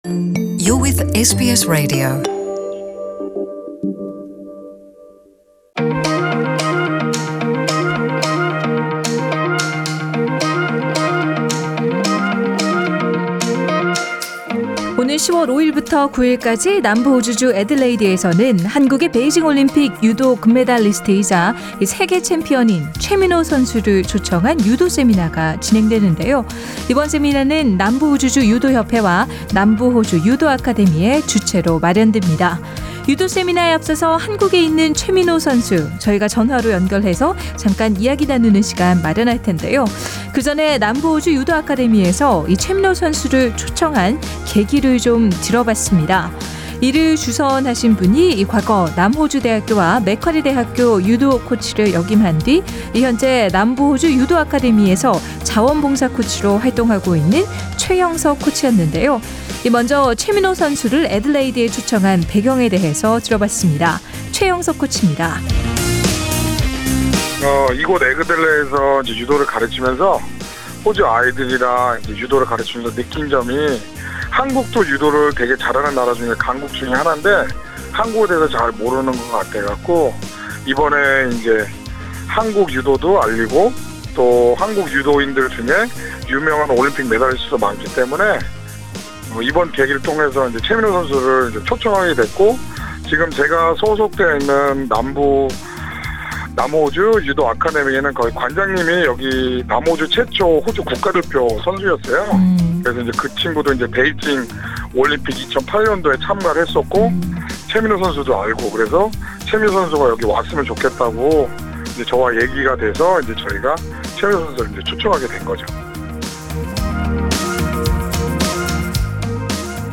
상단의 팟 캐스트를 통해 전체 인터뷰를 들으실 수 있습니다.